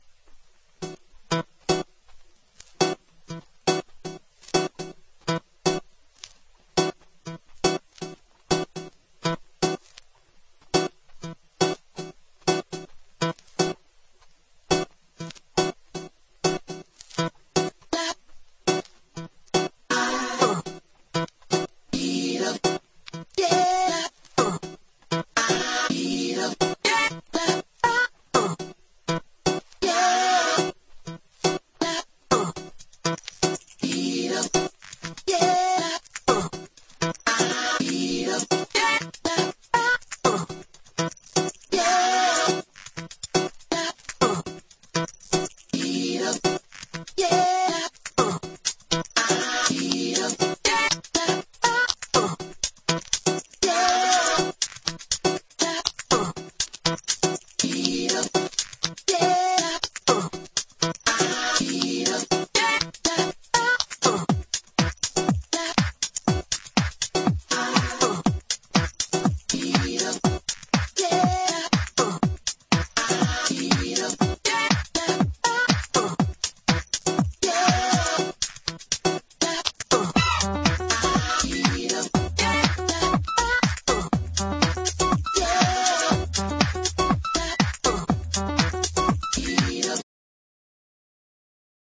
DEEP HOUSE / EARLY HOUSE# LATIN HOUSE